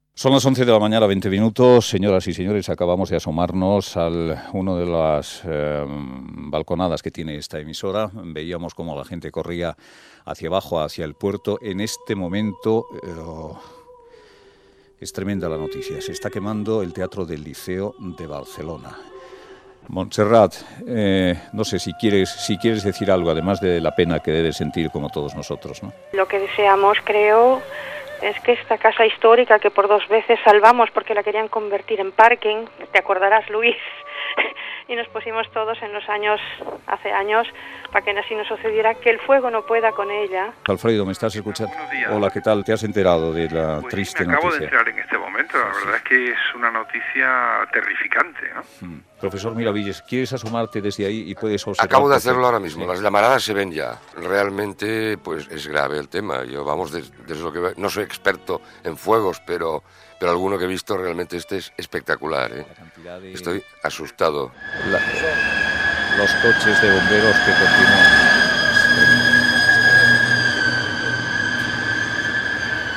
Resum de la informació que va donar Luis del Olmo de l'incendi del Gran Teatre del Liceu de Barcelona, amb la intervenció de Montserrat Caballé, Aldrefo Kraus i el professor Luis Miravitlles
Info-entreteniment